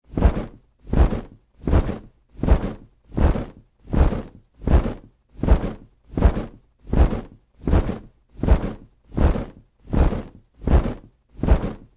На этой странице собраны умиротворяющие звуки Бога и ангелов — небесные хоры, божественные мелодии и атмосферные треки для медитации, релаксации или творческих проектов.